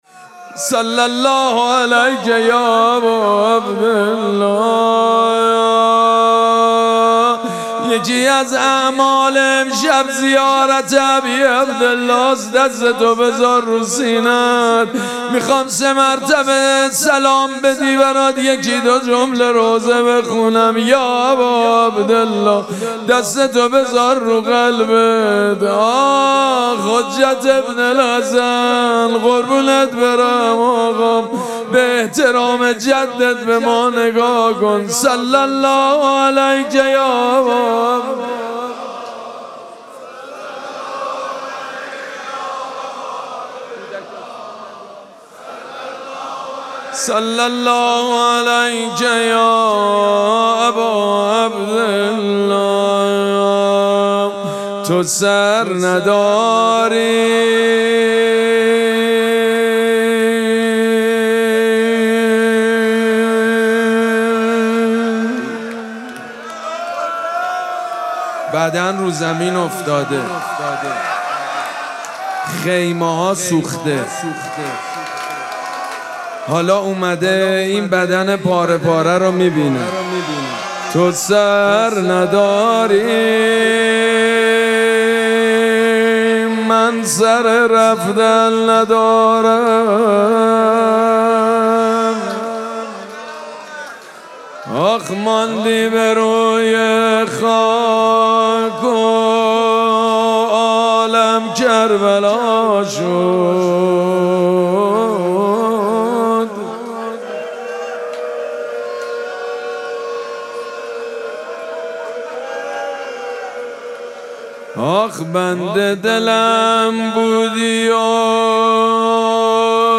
مراسم مناجات شب بیست و سوم ماه مبارک رمضان
روضه
حاج سید مجید بنی فاطمه